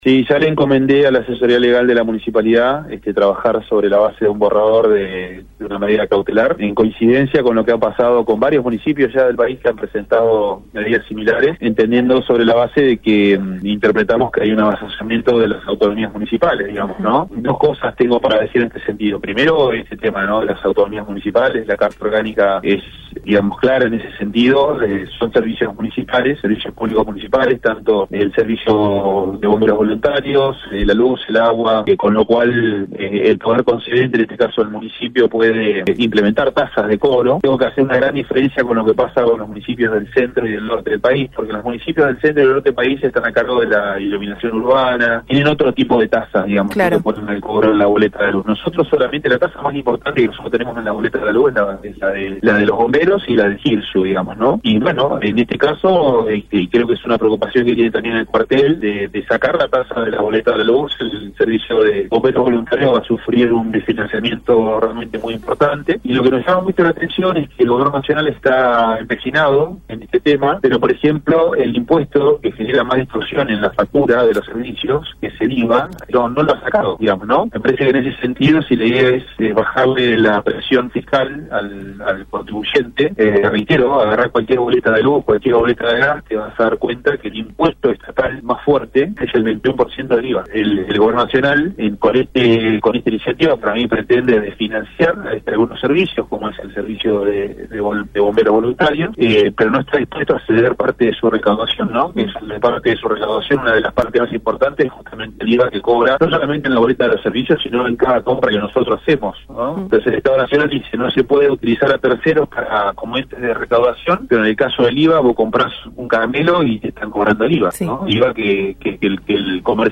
Así lo manifestó durante el programa Juntos en la Bahía al responder a la consulta realizada desde FM Bahía Engaño.